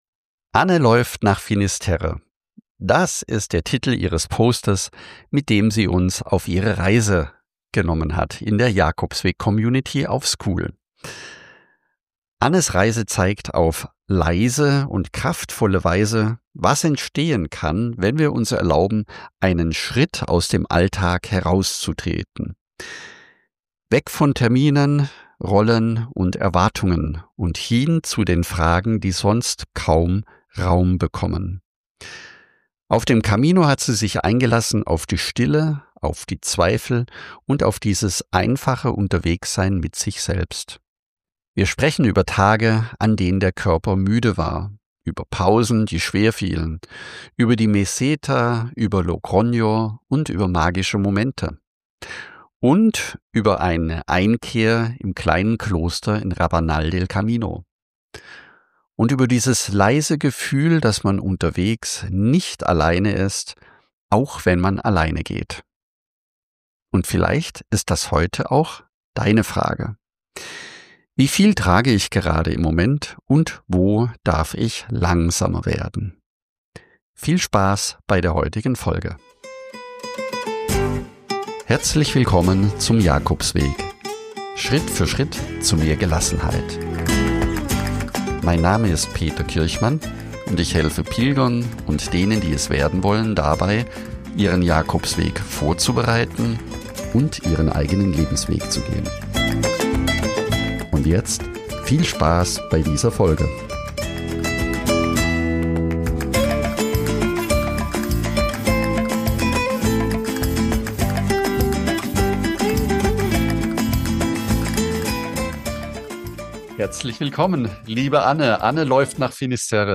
Über Erschöpfung, Pausen und das leise Vertrauen, unterwegs getragen zu sein. Ein Gespräch für alle, die gerade langsamer werden dürfen und sich fragen, was wirklich zählt.